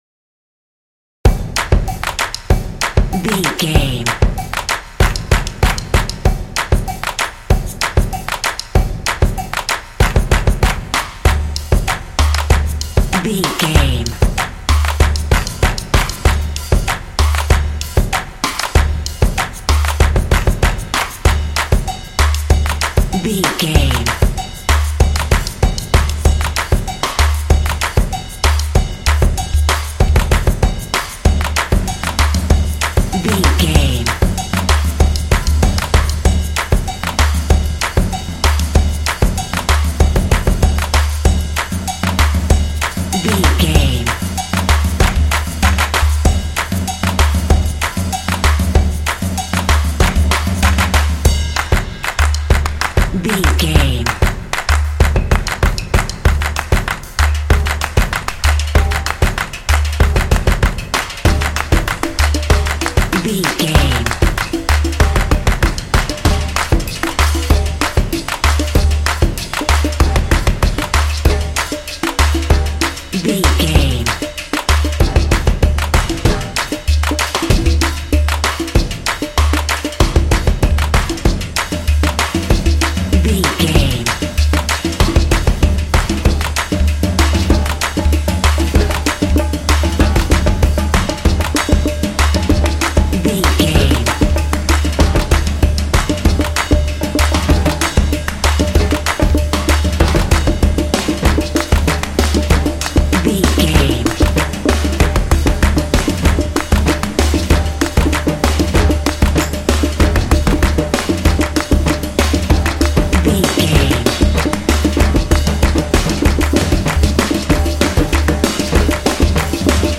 Atonal
Fast
driving
determined
drums
percussion